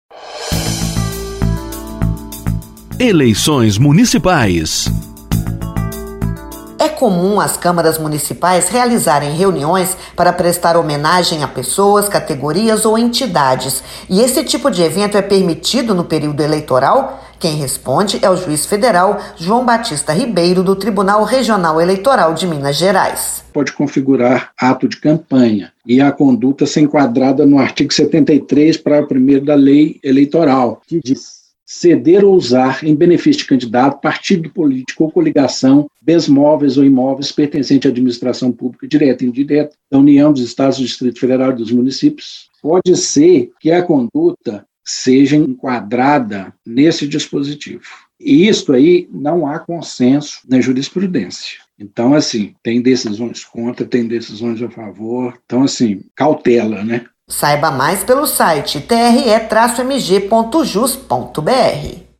Ouça a dica do Juiz Federal, João Batista Ribeiro.